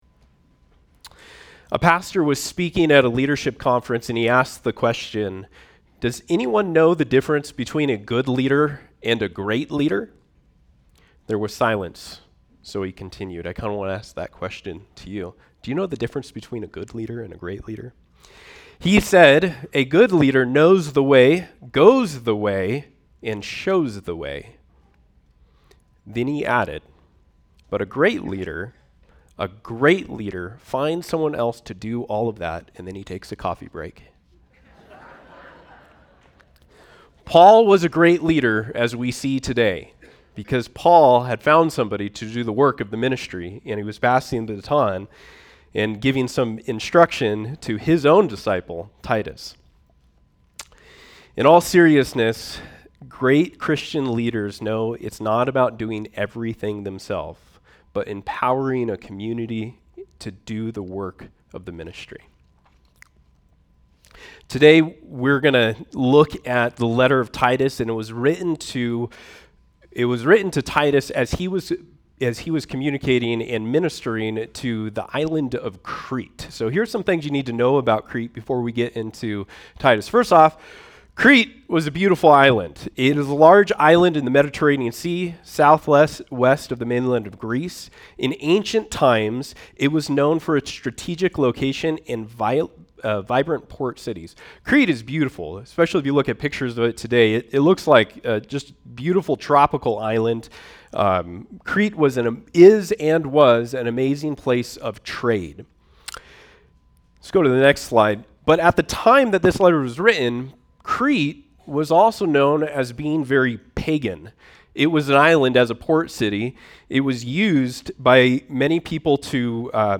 shares the next sermon